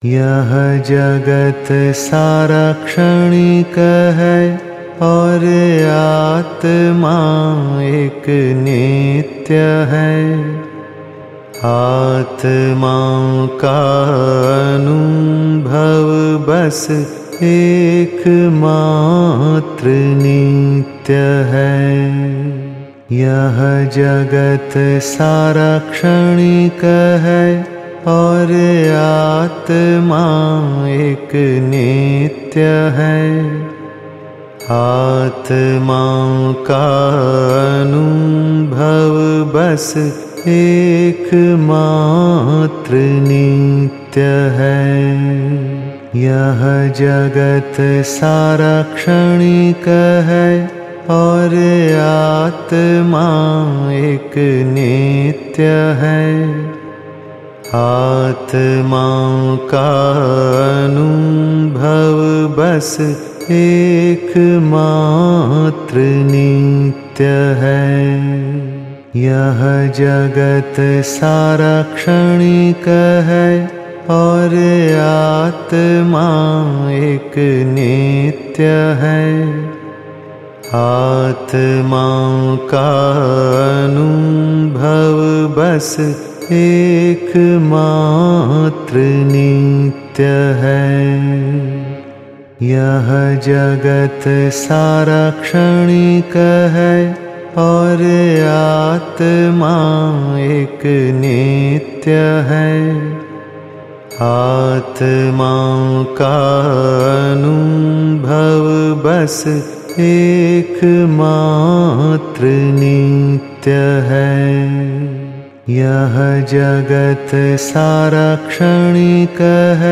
Mantra chanting The whole world is transient; only the soul is eternal.